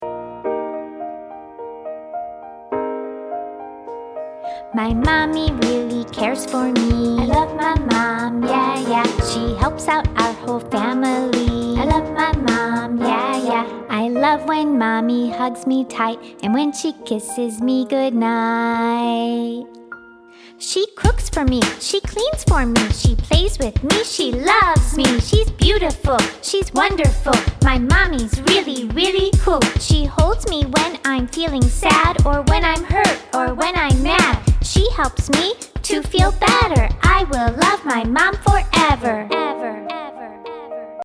Educational Songs by Subject